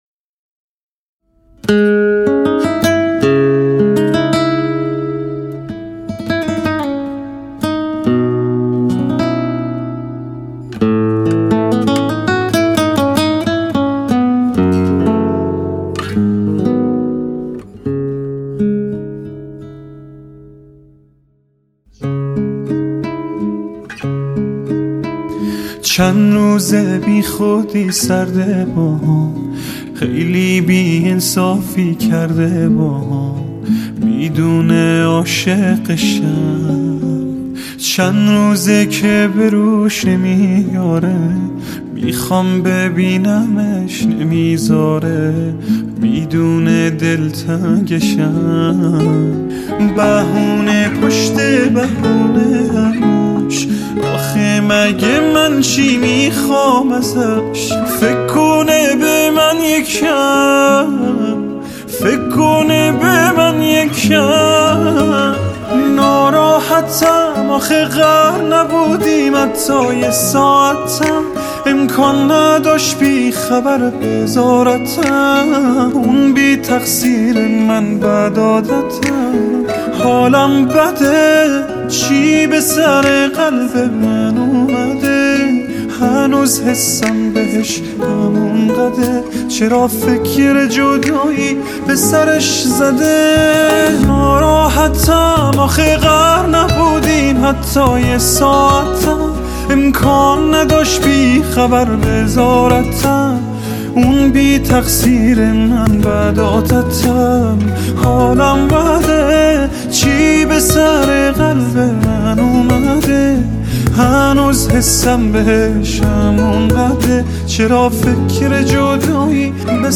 ریتم 6/8 سنگین